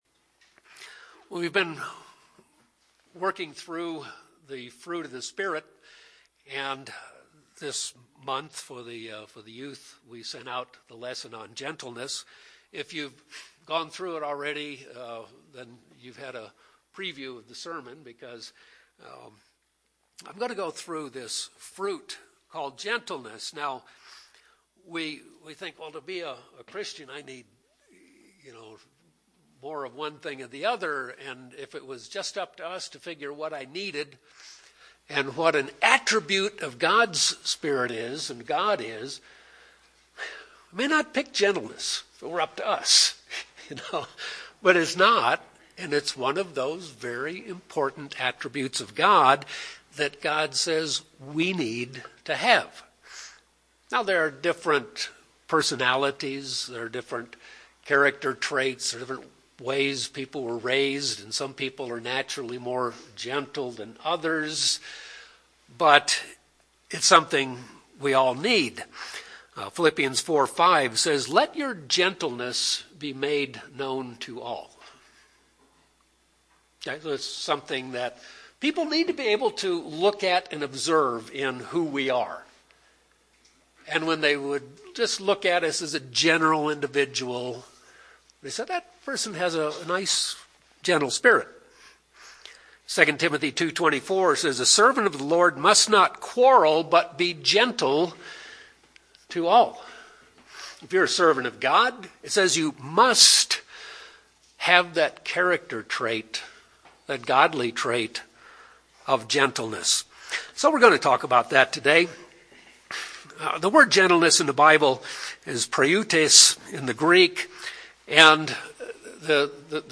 This sermon will discuss how we can develop this important Christ-like character trait.
Given in Albuquerque, NM